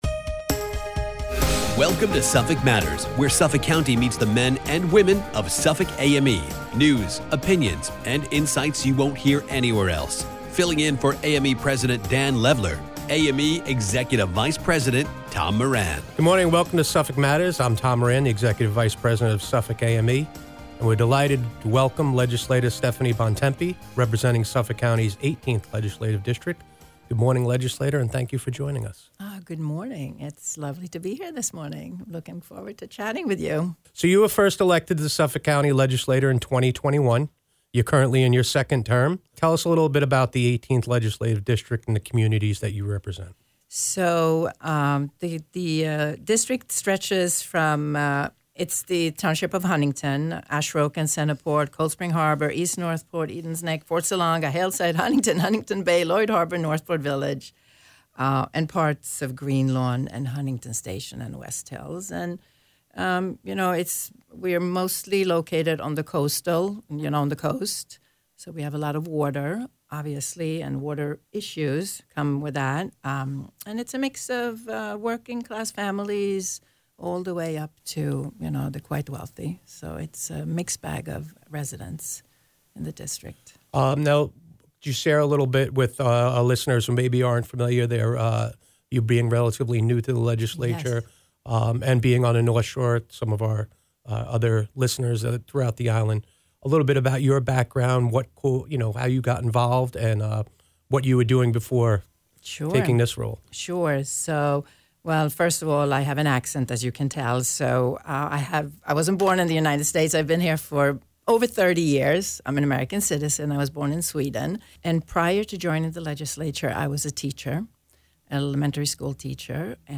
speaks with Suffolk County Legislator Stephanie Bontempi